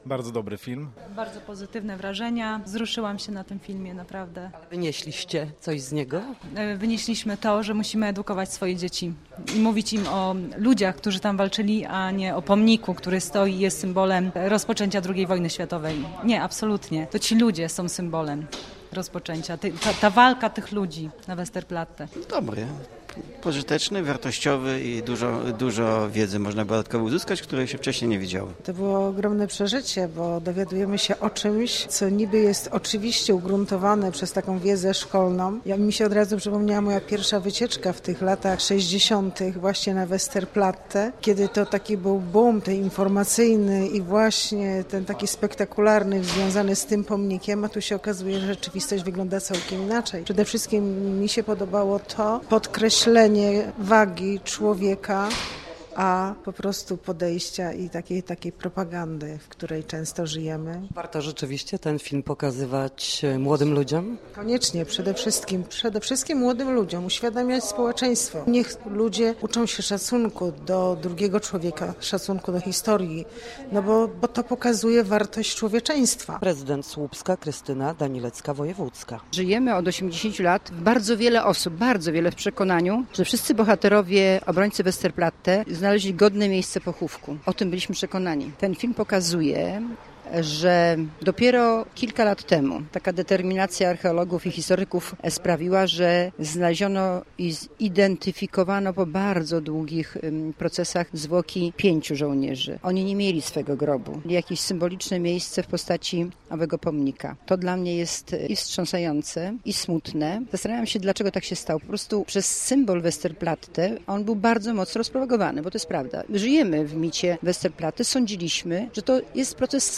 Po seansie tego ważnego filmu zapytaliśmy widzów o wrażenia i odczucia „na gorąco”.